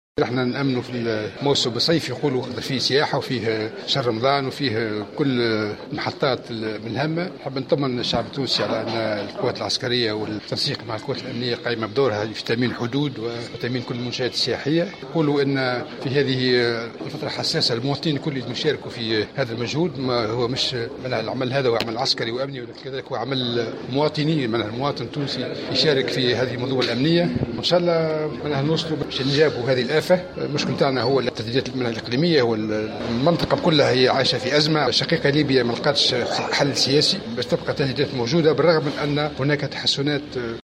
على هامش الاحتفال بمرور 20 سنة على تأسيس مجلة "حقائق"، اليوم في الحمامات